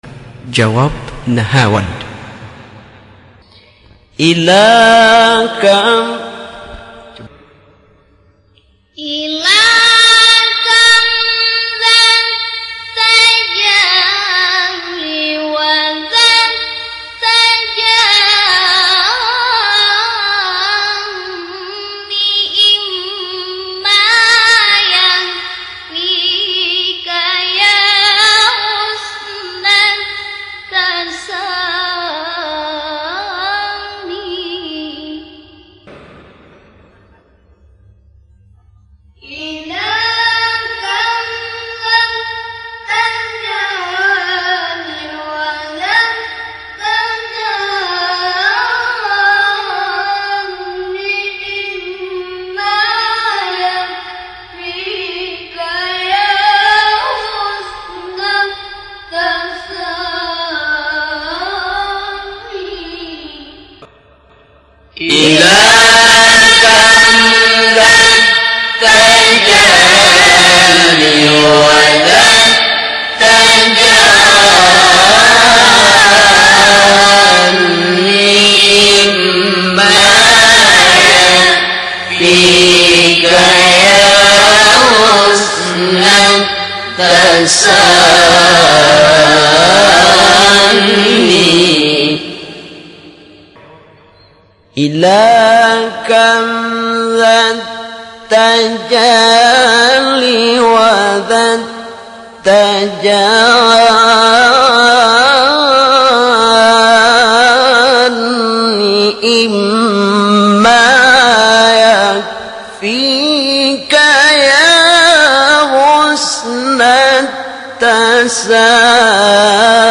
نهاوند اصلی جواب.mp3
نهاوند-اصلی-جواب.mp3